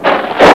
SMLCRAK2.WAV